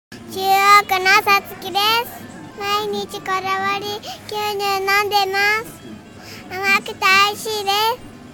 試飲いただいたお客様の生の声
リンクをクリックするとこだわり牛乳を試飲いただいた皆様からの感想を聞くことができます。
4月1日（火）15:00～18:00　スーパーアークス 菊水店
お客様の声1